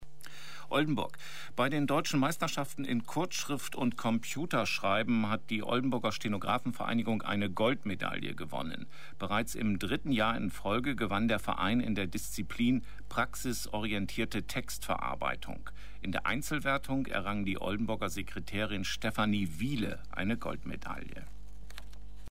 Die erste Berichterstattung im Radio erfolgte am 29. Mai 2006 um 17:00 Uhr in den Regionalnachrichten von NDR 1.